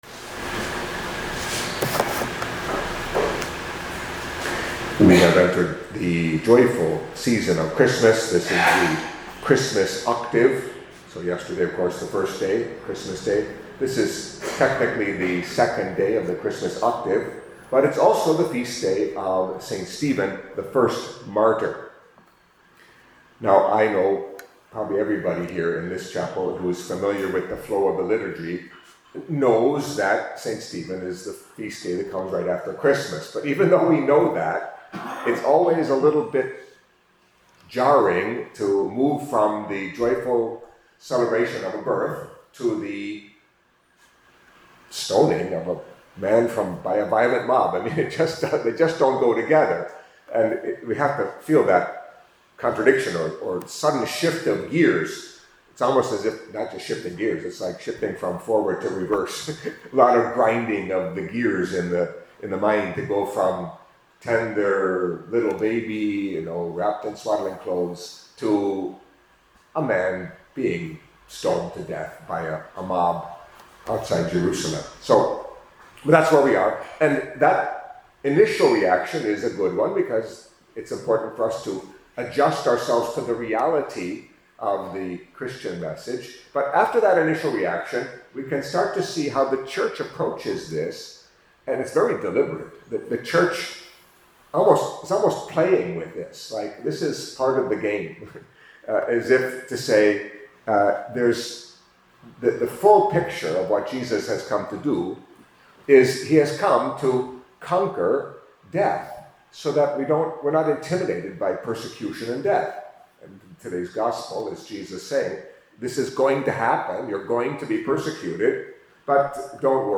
Catholic Mass homily for the Feast of Saint Stephen